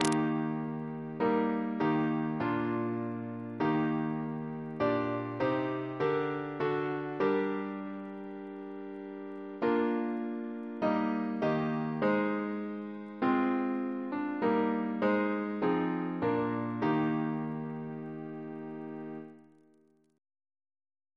Double chant in E♭ Composer: George Thalben-Ball (1896-1987), Organist of the Temple Church Reference psalters: ACP: 89